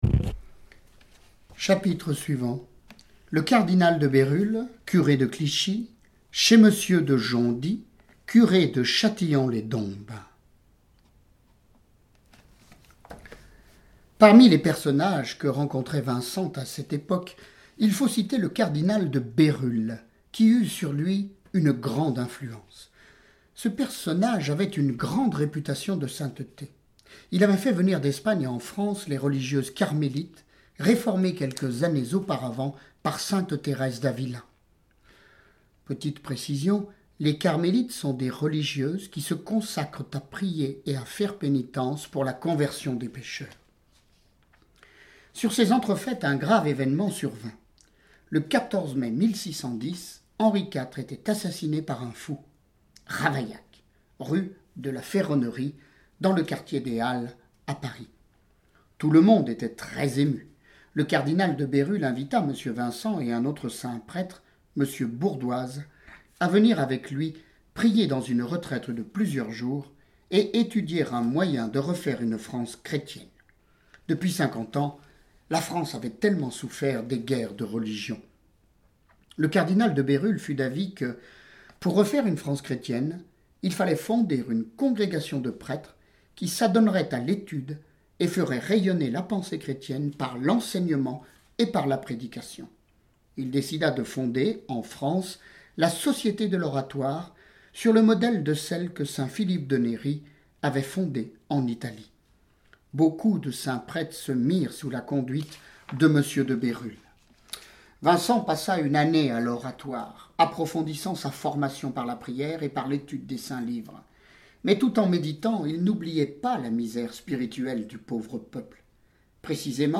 Lecture de vie de Saints et Saintes >> Saint Vincent de Paul